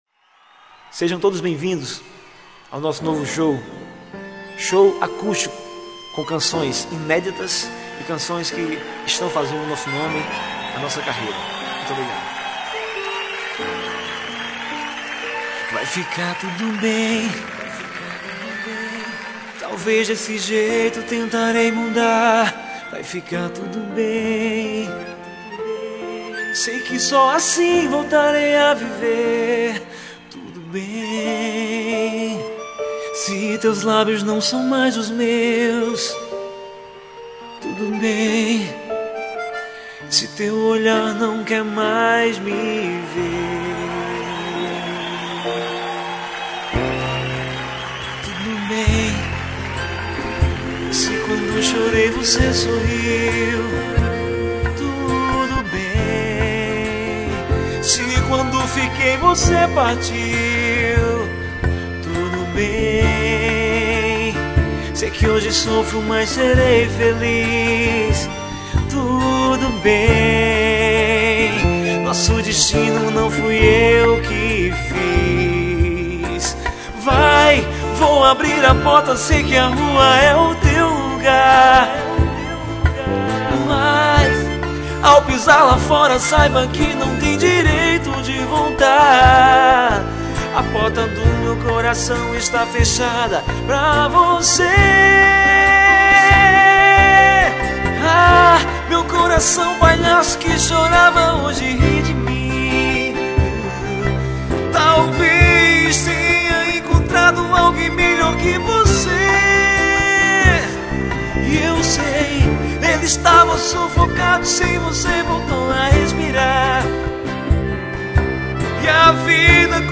EstiloMPB